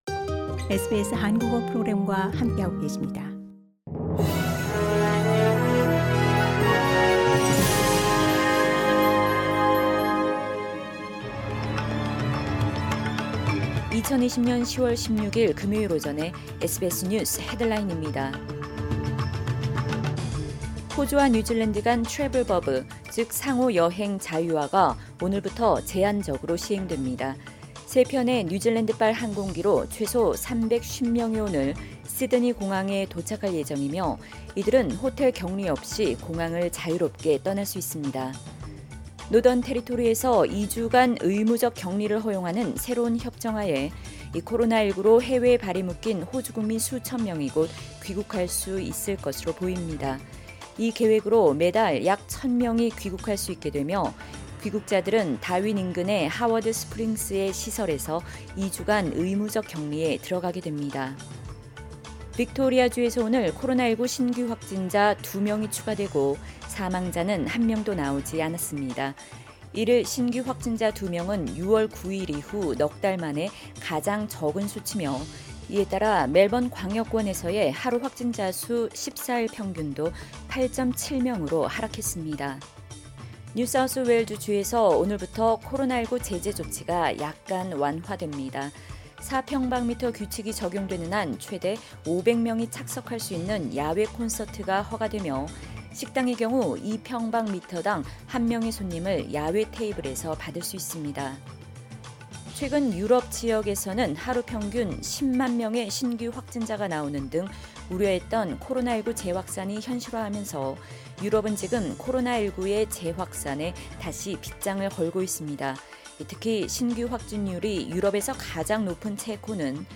2020년 10월 16일 금요일 오전의 SBS 뉴스 헤드라인입니다.